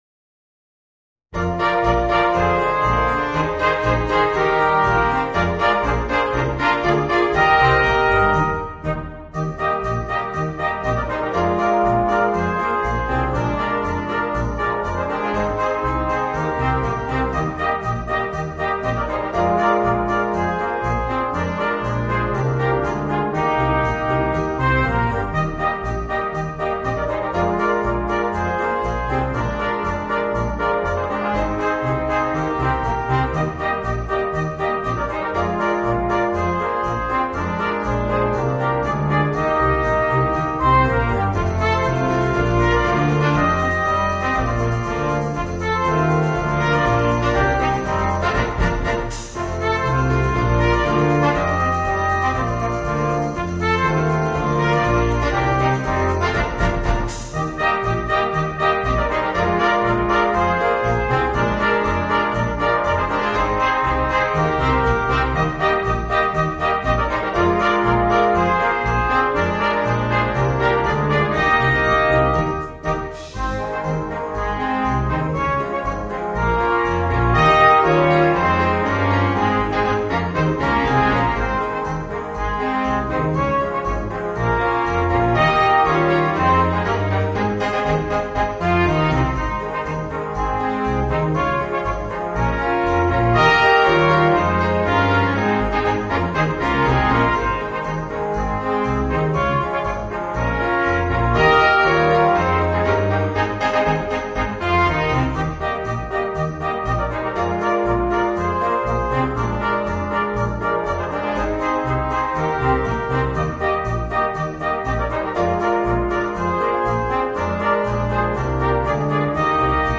Voicing: 5 - Part Ensemble and Piano / Keyboard